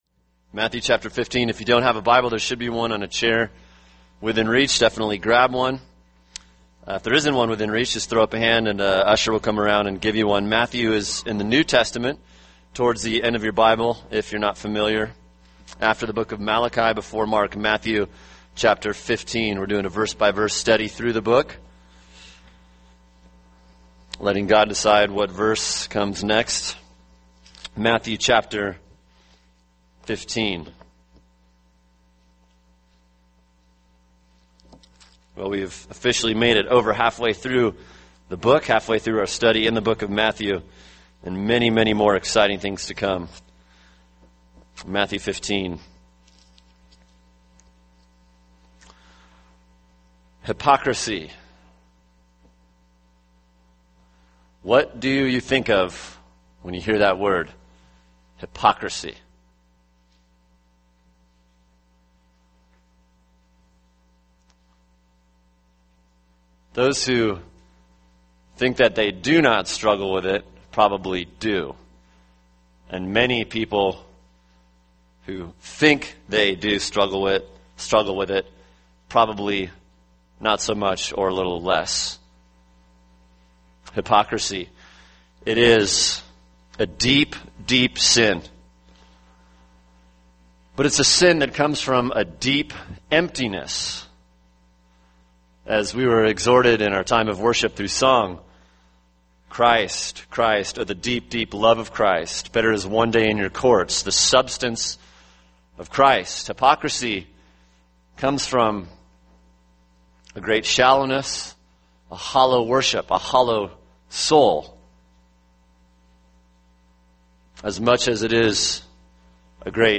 [sermon] Matthew 15:1-9 – Characteristics of a Hypocrite | Cornerstone Church - Jackson Hole